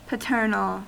Ääntäminen
Synonyymit fatherlike Ääntäminen US Tuntematon aksentti: IPA : /pəˈtɜː(r)nəl/ Haettu sana löytyi näillä lähdekielillä: englanti Käännöksiä ei löytynyt valitulle kohdekielelle.